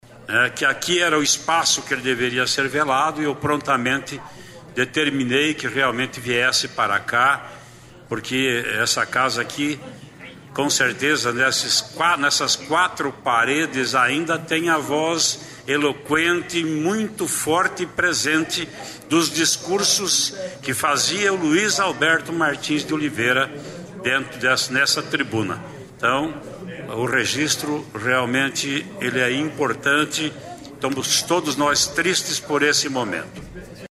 Traiano lamenta morte de ex-deputado em plenário
Depois de declarar luto oficial de três dias pela morte do ex-deputado estadual e ex-senador Luiz Alberto Martins de Oliveira, o presidente da Assembleia Legislativa, deputado Ademar Traiano (PSDB), se manifestou em plenário, sobre a importância que teve o político na história do Paraná e sobre o fato de ter aberto a Casa para o velório.
(Sonora)